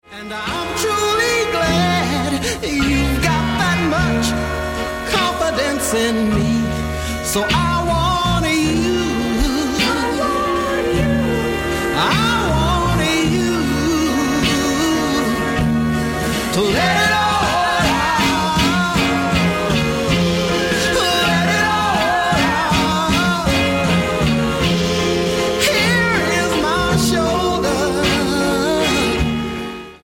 Lowrider Soul